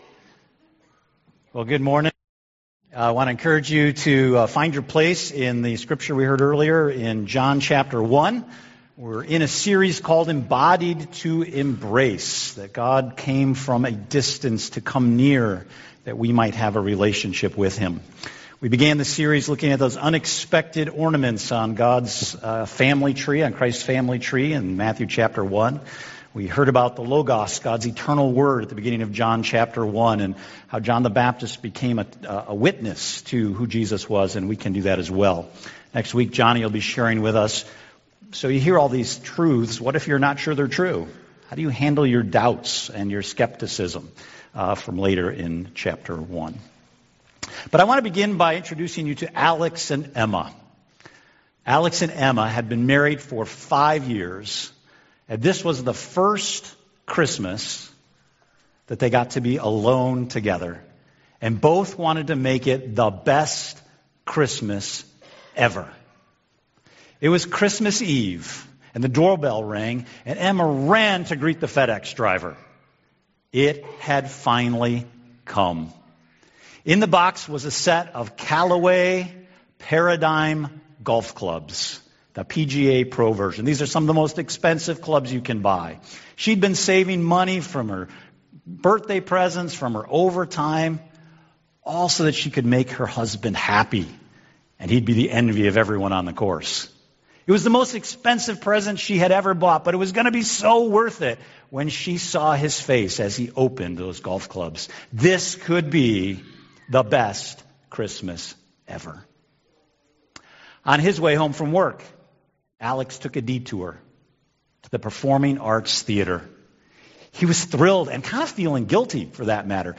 Embodied to Embrace Service Type: Sunday Morning « Celebrate God Times!